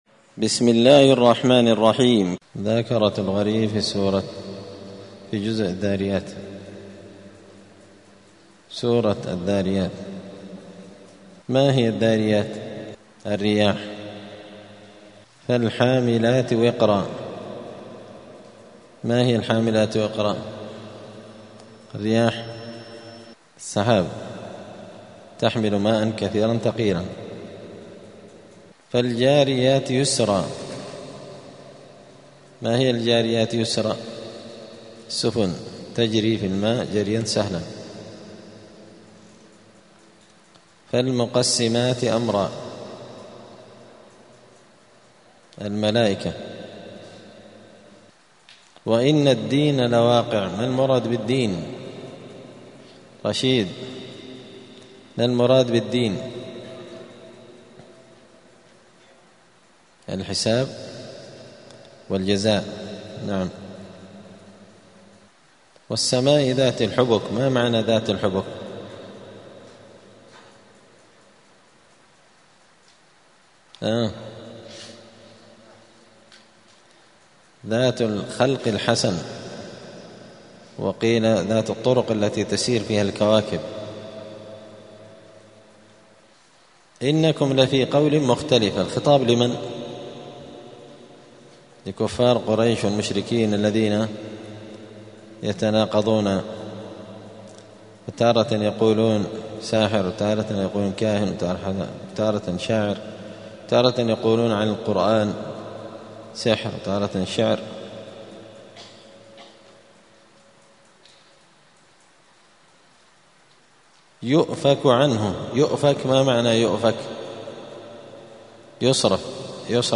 *مذاكرة لغريب القرآن في رمضان*
دار الحديث السلفية بمسجد الفرقان قشن المهرة اليمن